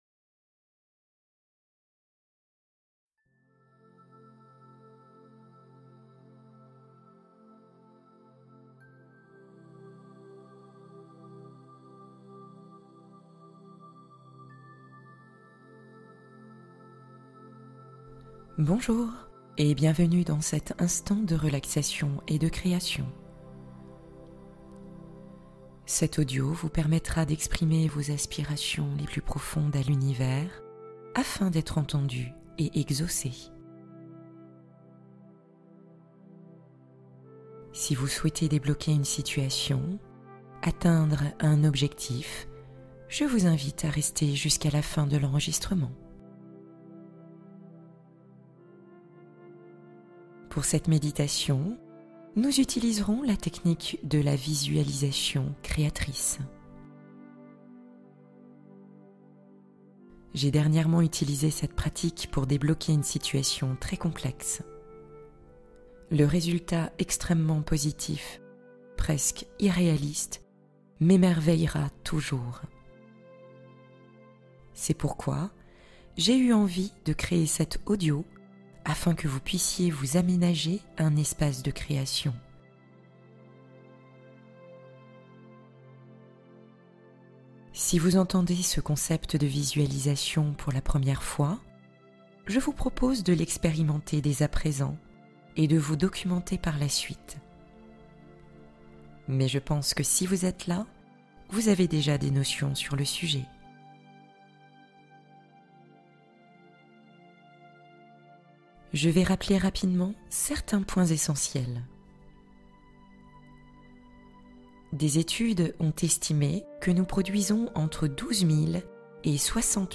Soutenir un proche à distance : méditation guidée pour renforcer le lien émotionnel